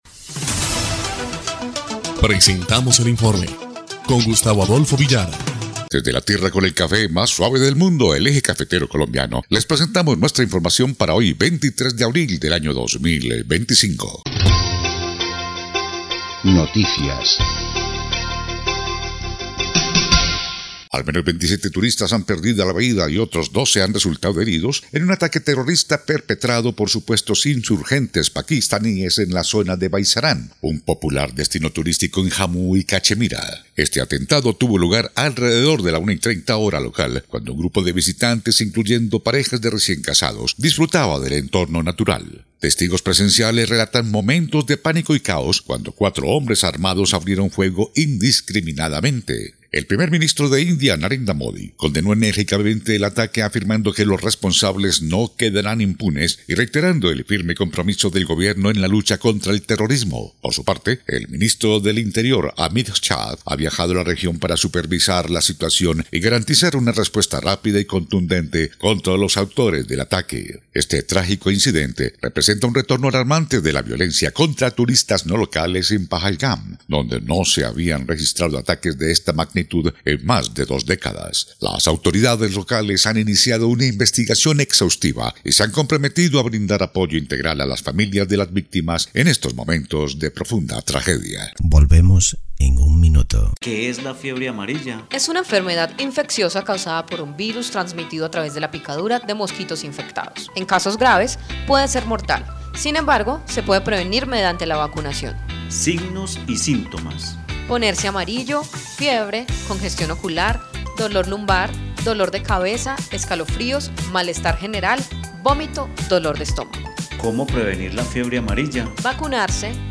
EL INFORME 1° Clip de Noticias del 23 de abril de 2025